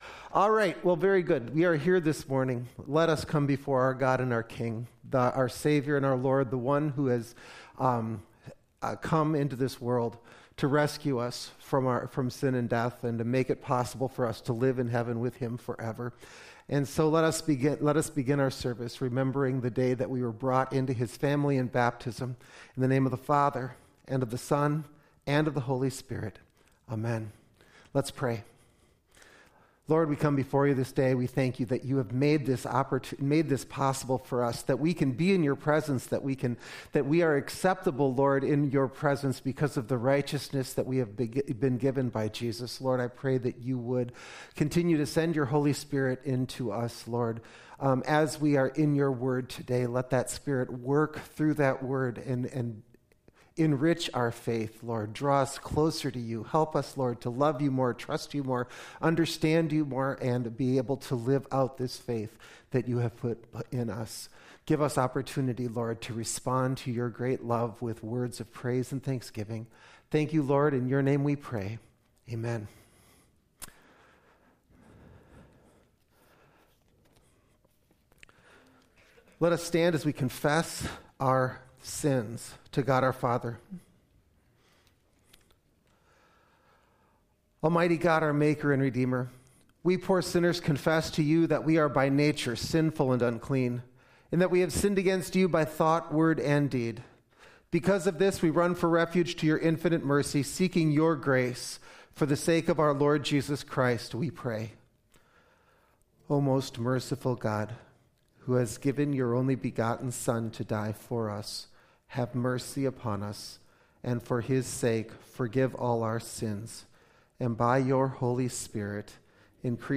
01-02-22 Worship Service - St. Mark's Lutheran Church and School
2022-January-2-Complete-Service.mp3